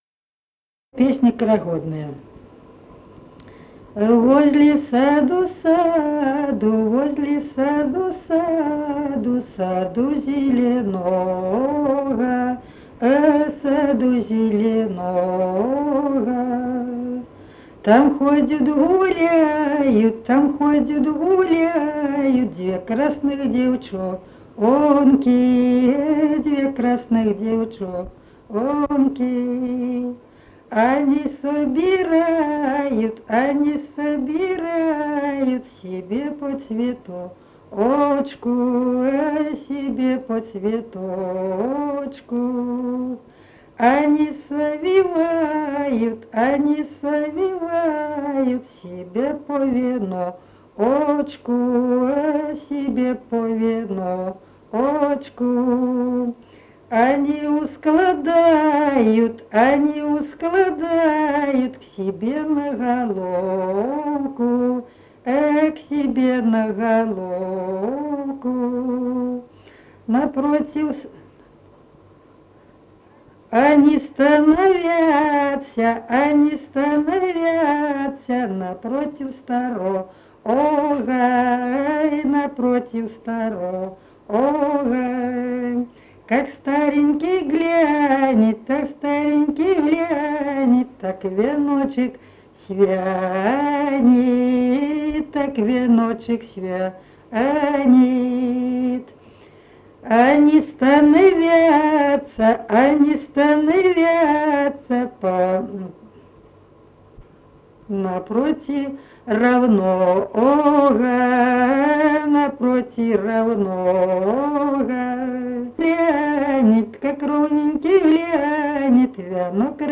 Народные песни Смоленской области
«Возле саду, саду» («карагодная» вечерочная).